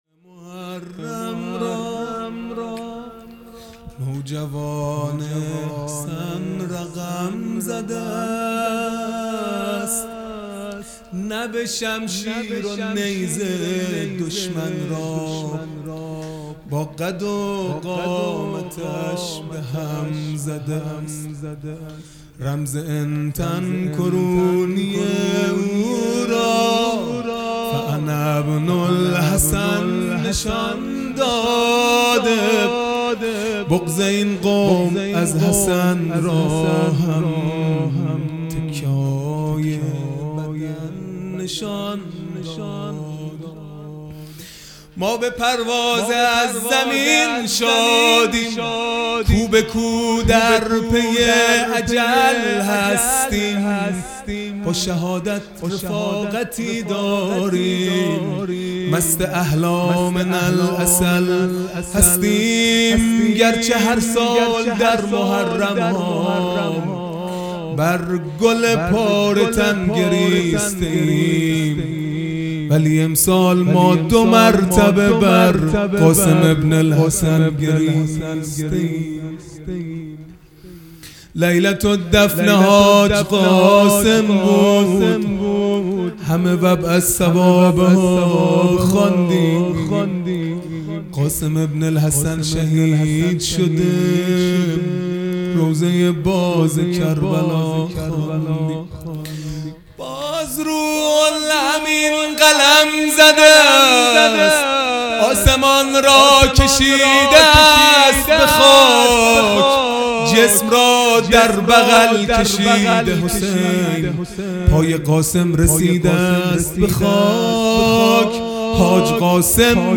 خیمه گاه - هیئت بچه های فاطمه (س) - مناجات | محرم را نوجوان حسن رقم زده است
دهه اول محرم الحرام ۱۴۴٢ | شب ششم